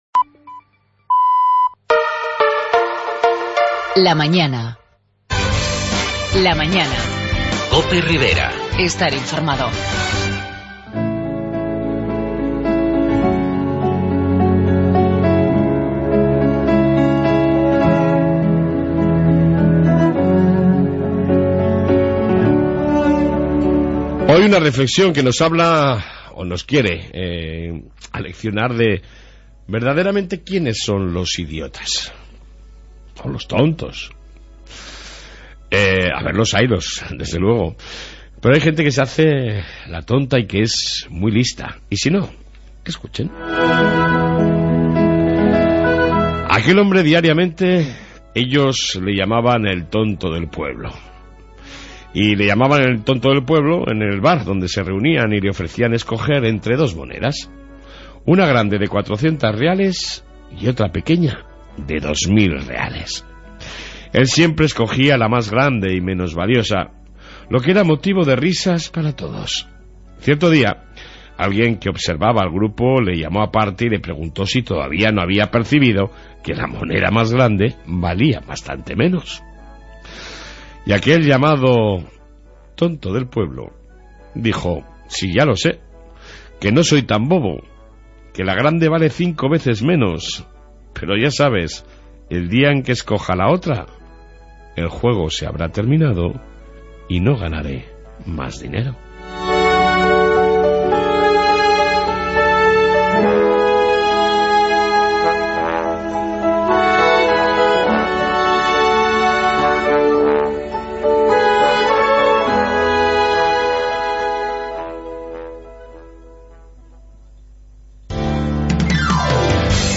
AUDIO: En esta 1ª parte Reflexión diaria y entrevista